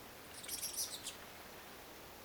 keltasirkun sarjakiistelyääni